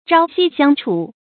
朝夕相处 zhāo xī xiāng chǔ 成语解释 从早到晚都在一起。